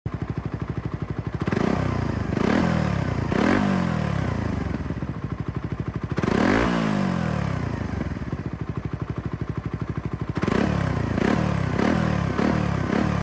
LeoVince Cobra Exhaust Sound